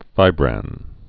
(fībrăn)